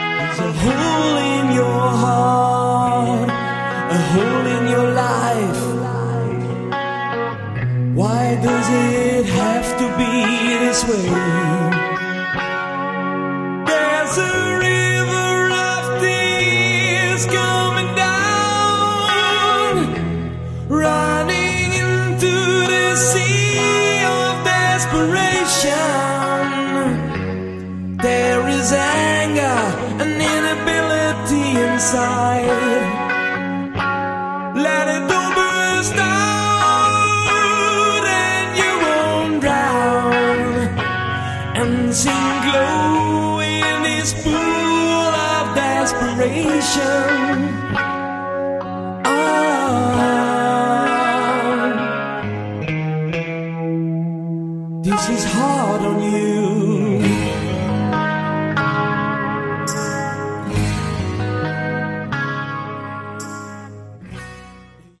Category: Melodic Metal
vocals
guitars
bass
drums